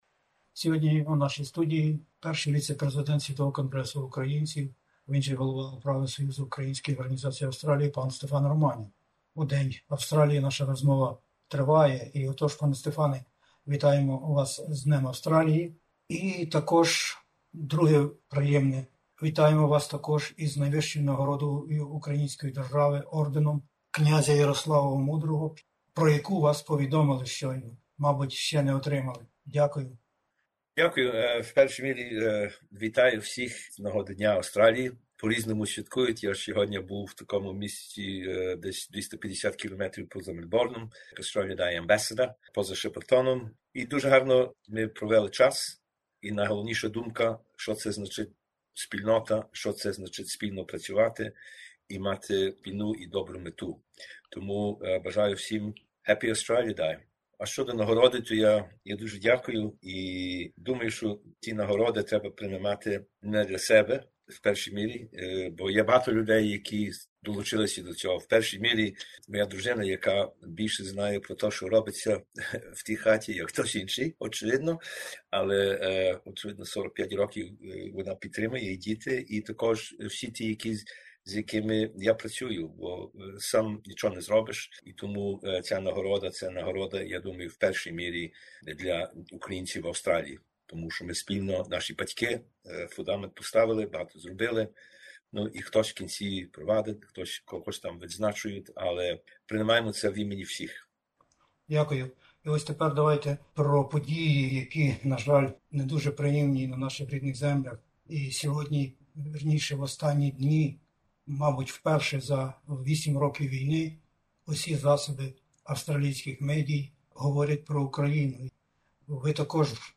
Єднання українців та міжнародної спільноти, тривоги і надії, прохання про підтримку і міжнародні акції, всесвітнє молитовне єднання за мир і спокій на землях українських... І не тільки про це у розмові на хвилях SBS Ukrainian